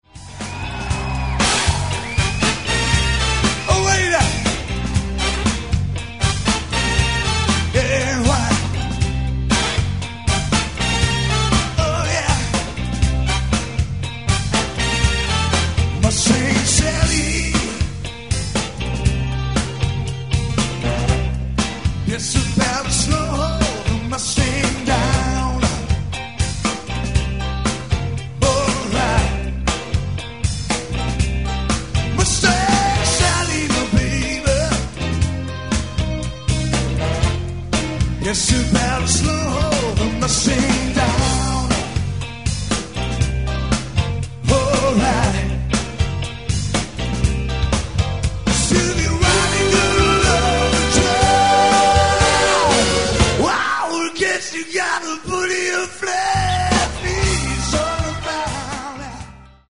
CD-LIVE